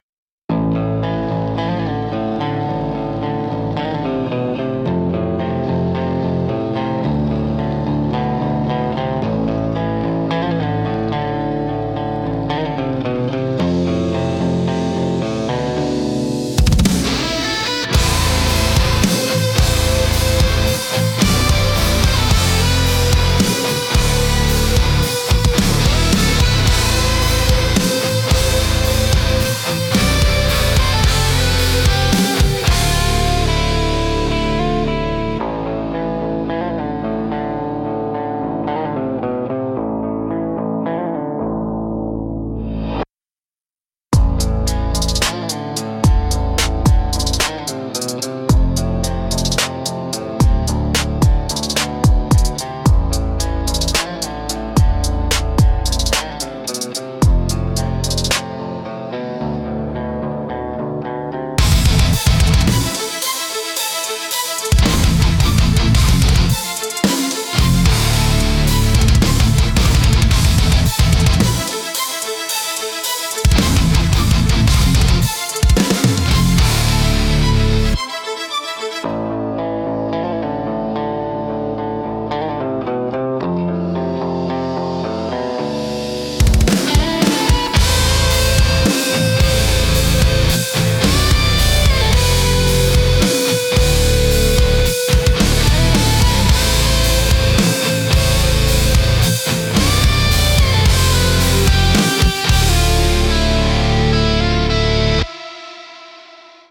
Instrumental - A Riff at the Edge of Nowhere